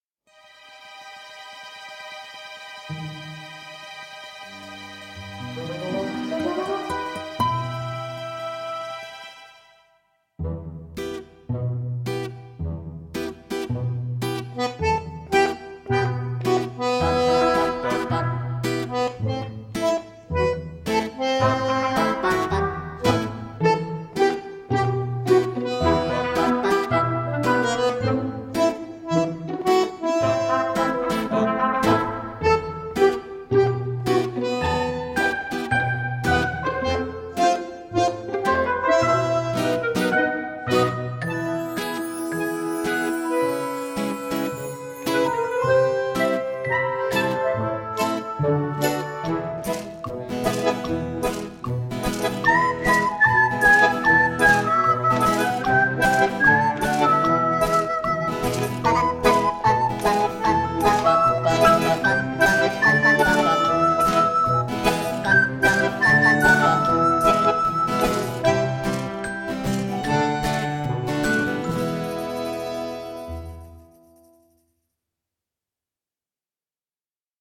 un morceau de la bande originale